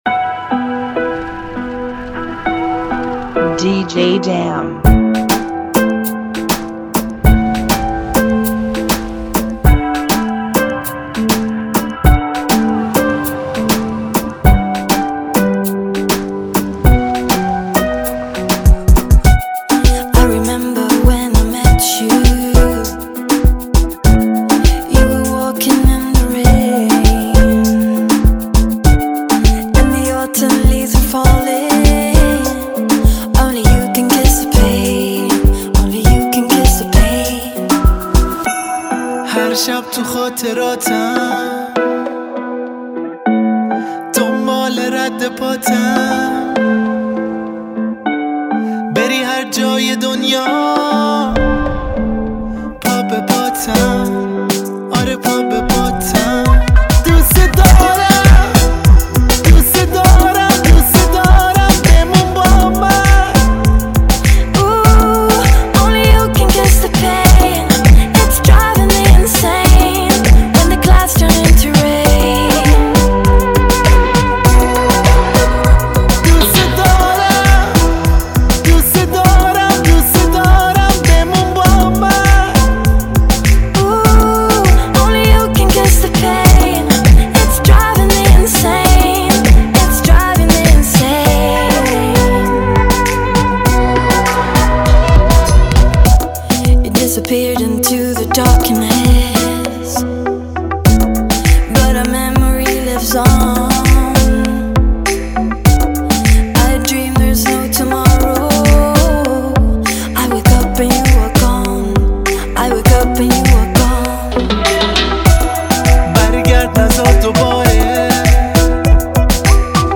(100 BPM)
Genre: Kizomba Remix